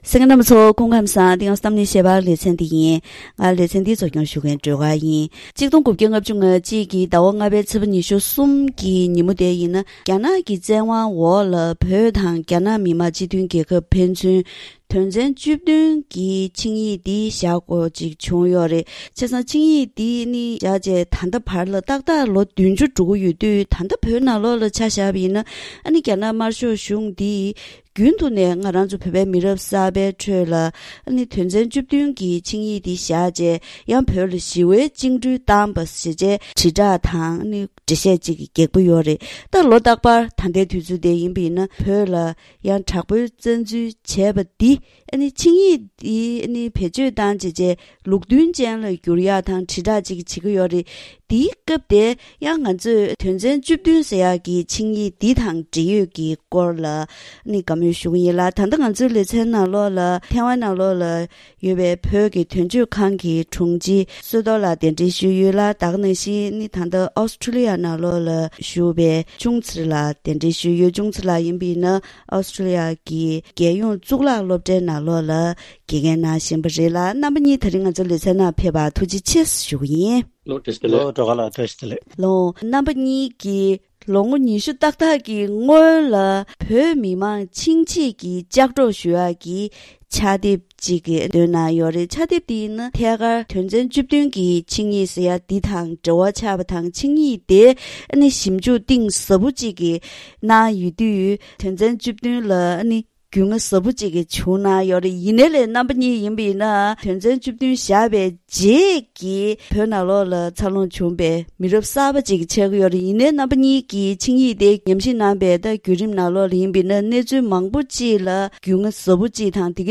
༄༅།།དེ་རིང་གི་གཏམ་གླེང་ཞལ་པར་ལེ་ཚན་ནང་སྤྱི་ལོ་༡༩༥༡ལོའི་ཟླ་༥ཚེས་༢༣ཉིན་རྒྱ་ནག་གི་བཙན་དབང་འོག་བོད་དང་རྒྱ་ནག་མི་དམངས་སྤྱི་མཐུན་རྒྱལ་ཁབ་ཕན་ཚུན་དོན་ཚན་བཅུ་བདུན་གྱི་ཆིངས་ཡིག་འདི་བཞག་དགོས་བྱུང་ཡོད།